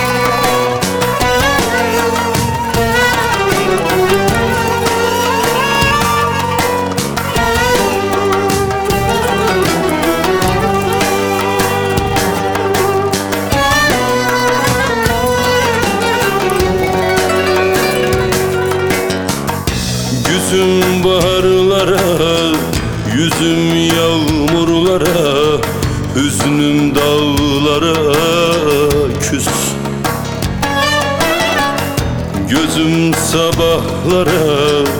Жанр: Турецкая поп-музыка